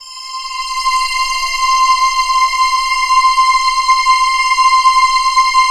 ALPS C6.wav